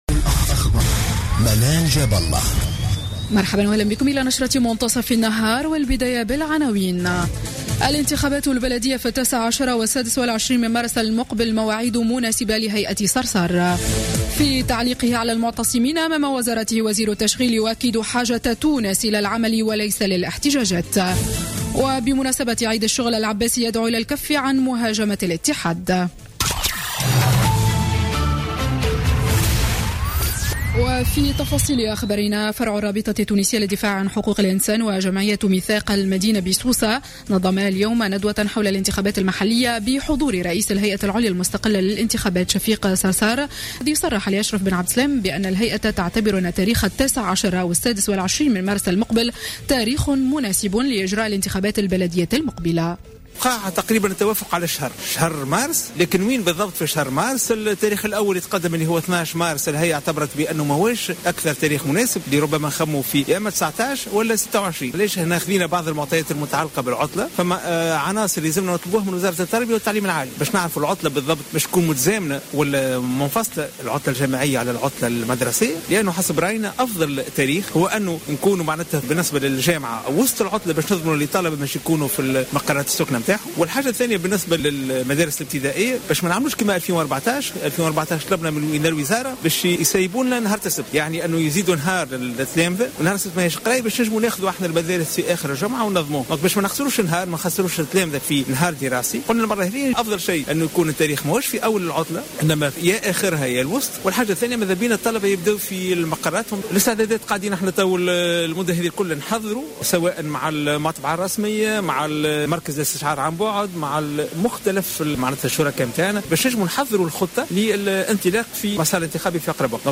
نشرة أخبار منتصف النهار ليوم السبت 30 أفريل 2016